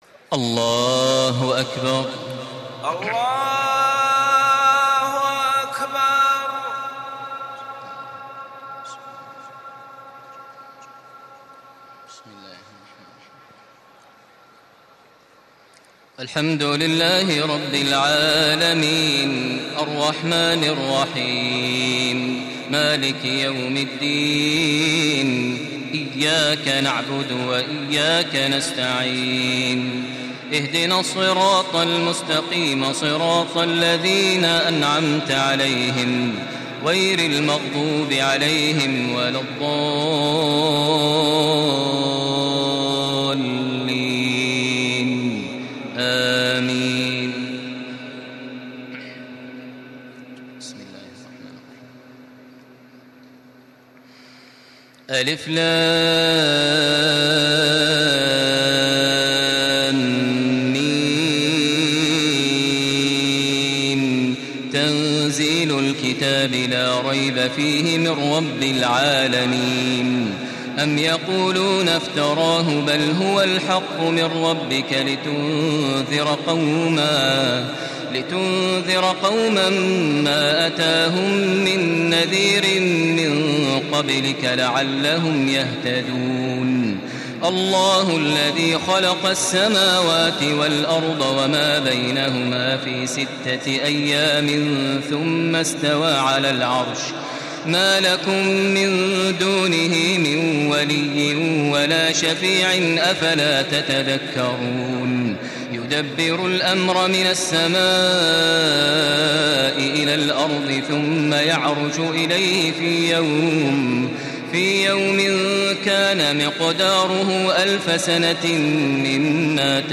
تراويح ليلة 21 رمضان 1435هـ من سور السجدة و الأحزاب (1-49) Taraweeh 21 st night Ramadan 1435H from Surah As-Sajda and Al-Ahzaab > تراويح الحرم المكي عام 1435 🕋 > التراويح - تلاوات الحرمين